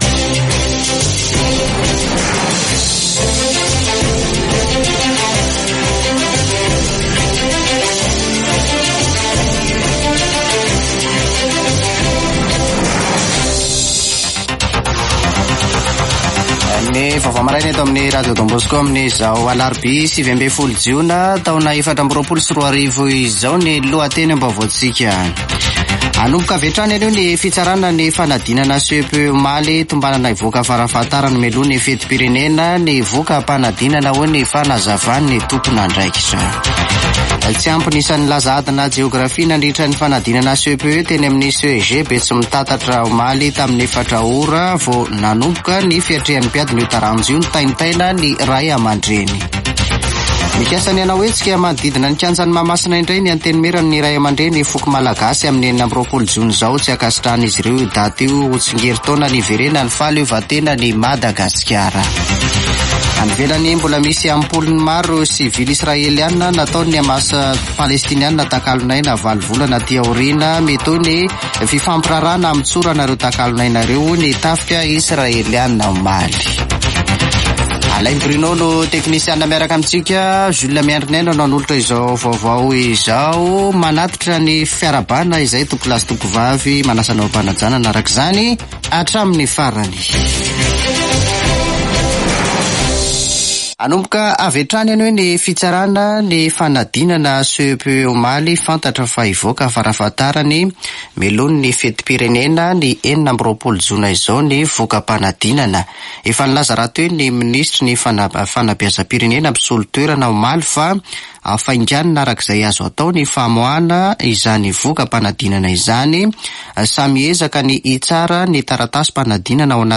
[Vaovao maraina] Alarobia 19 jona 2024